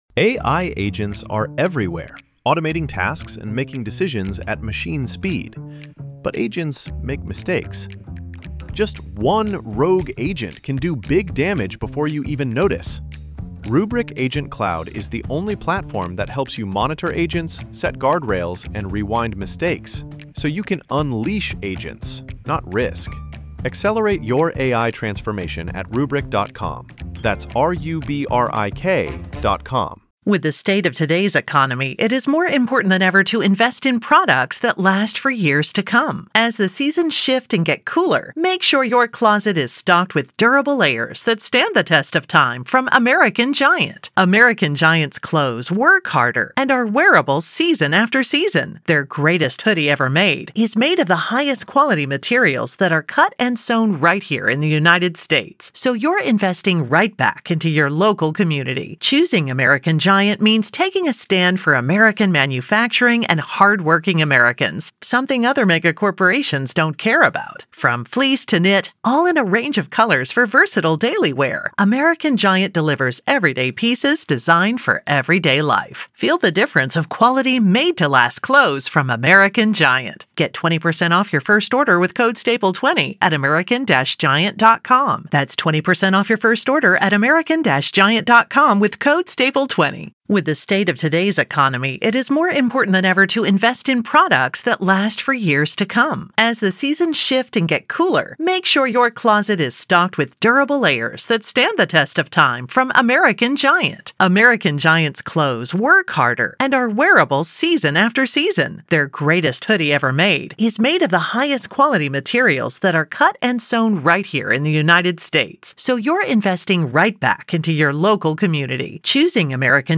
MA v. Karen Read Murder Retrial - Dog Bite Expert Daubert Hearing PART 2